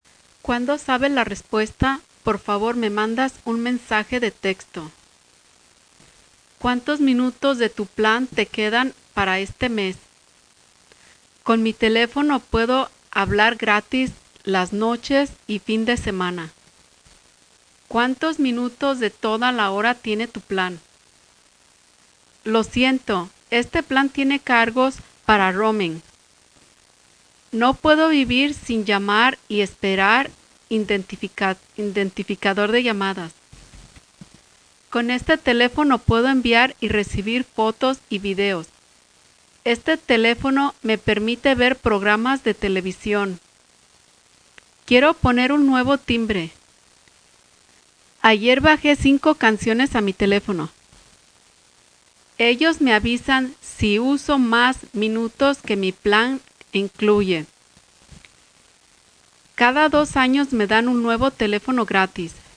As you listen to the audio file for the phrases on the page, you'll see it's recorded by a native Spanish speaker from Mexico. You can feel free to listen to this file as much as you want and have an authentic example of perfect Spanish pronunciation.